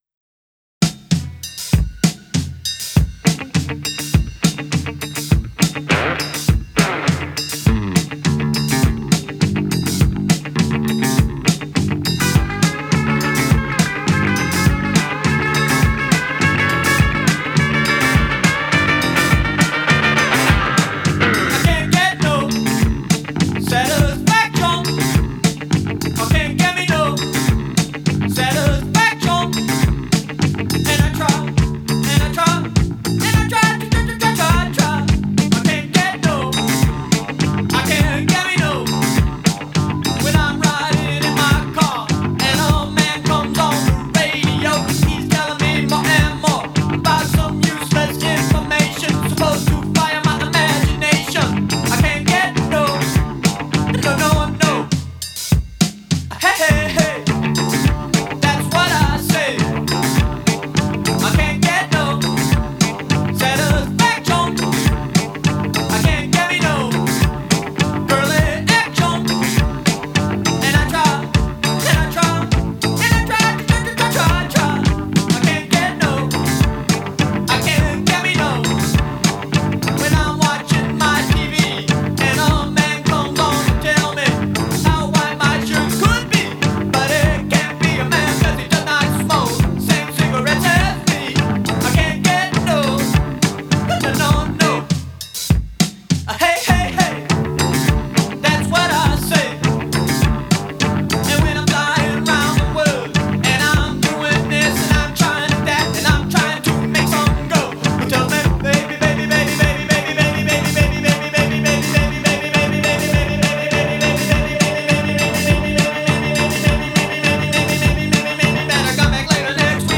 punky and funky.